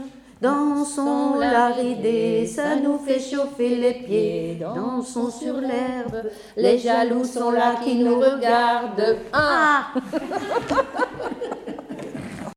Chanté lors d'une veillée le 22 septembre 2012
recueil de chansons sur l'île de Sein
Pièce musicale inédite